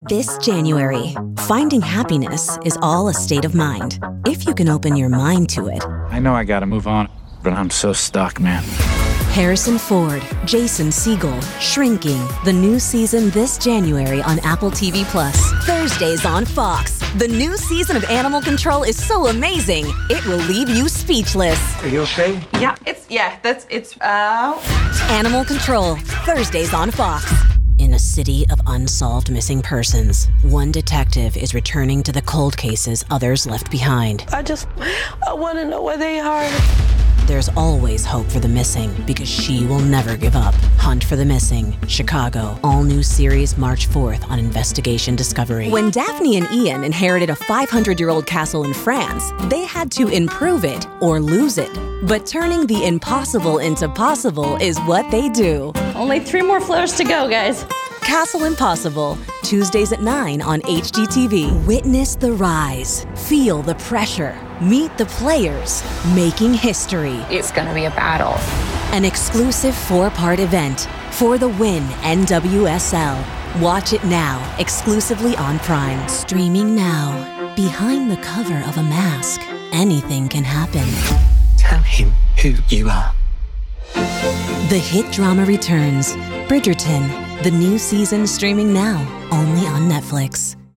chicago : voiceover : commercial : women